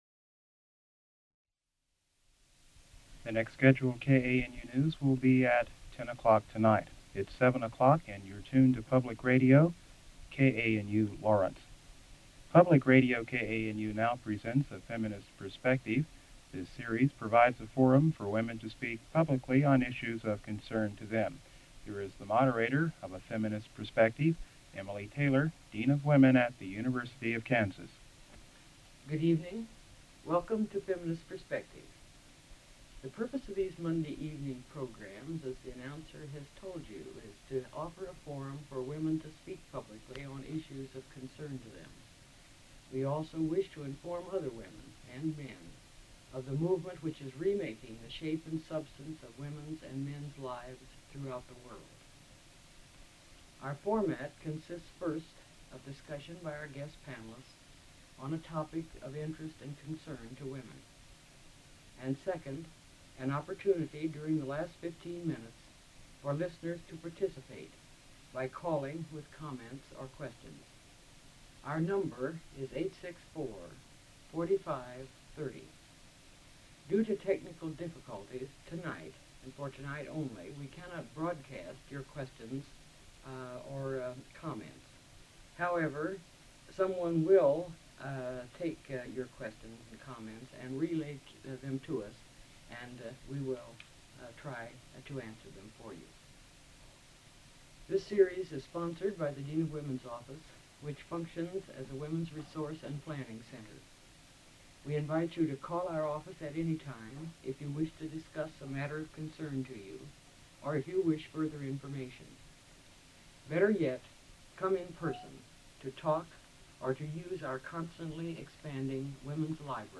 Radio talk shows